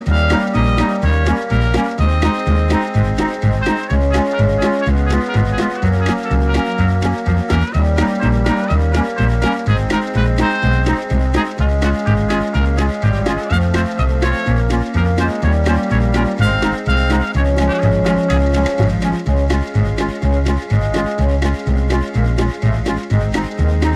No Ukulele Oldies (Male) 3:05 Buy £1.50